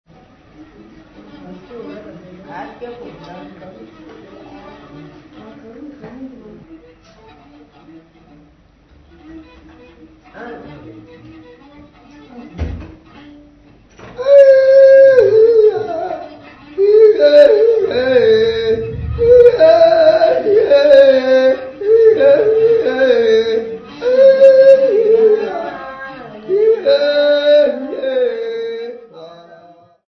Folk music--Africa
Field recordings
Africa Nambia Gobabis f-sx
sound recording-musical
Indigenous music.
3.75ips reel